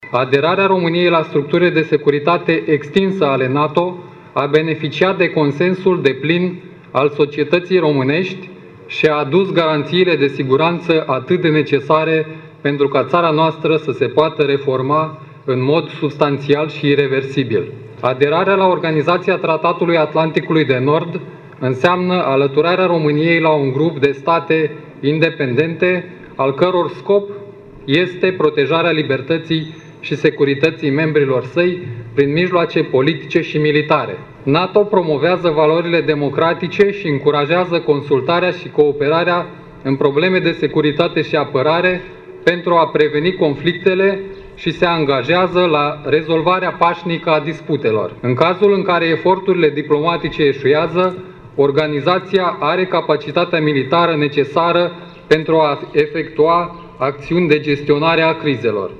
La Iași, s-au desfășurat, cu acest prilej, manifestări în Piața Tricolorului din fața Prefecturii, unde au fost arborate drapelul românesc și steagul NATO.
Prefectul Marian Grigoraș a subliniat faptul că NATO este o organizație importantă de securitate în lume și care, prin membrii ei, poate să facă față provocărilor actuale: